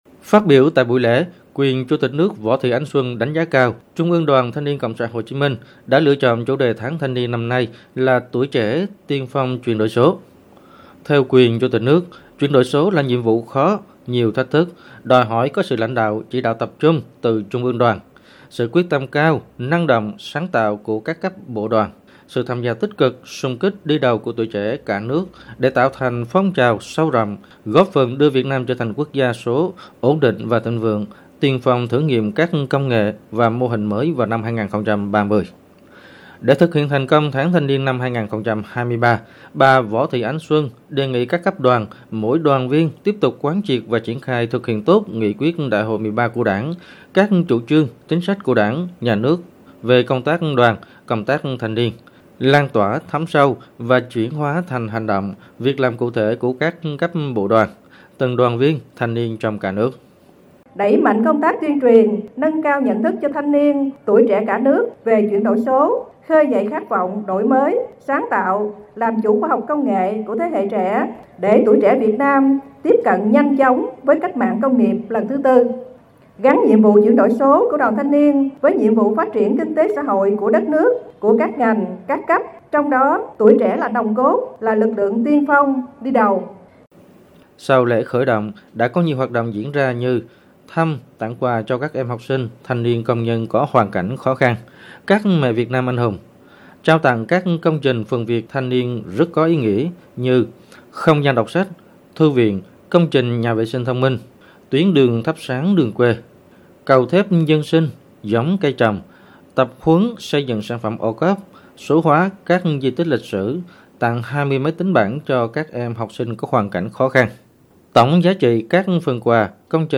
THỜI SỰ Tin thời sự
VOV1 - Sáng 26/2, tại thị trấn Ma Lâm, huyện Hàm Thuận Bắc, tỉnh Bình Thuận, Trung ương Đoàn TNCS Hồ Chí Minh tổ chức Lễ khởi động Tháng Thanh niên 2023 với chủ đề “Tuổi trẻ tiên phong chuyển đổi số”. Quyền Chủ tịch nước Võ Thị Ánh Xuân dự và phát biểu tại buổi lễ.